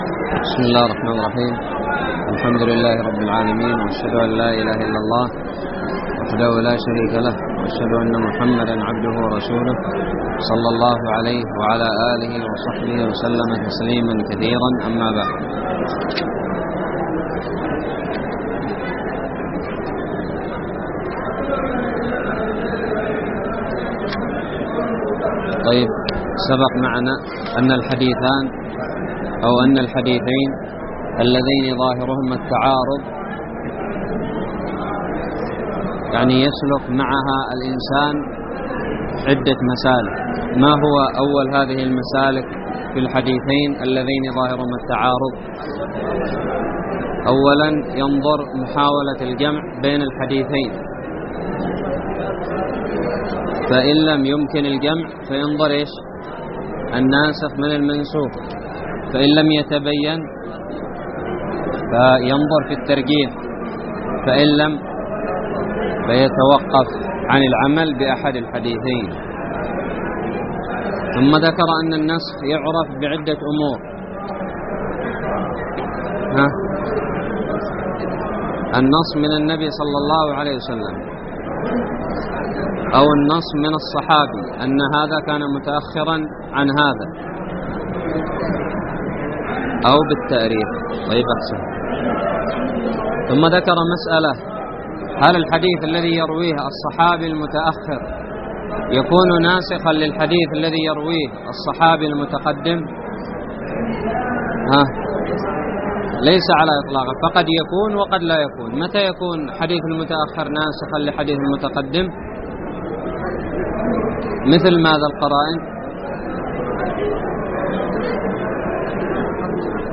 الدرس العشرون من شرح كتاب نزهة النظر
ألقيت بدار الحديث السلفية للعلوم الشرعية بالضالع